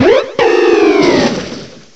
cry_not_decidueye.aif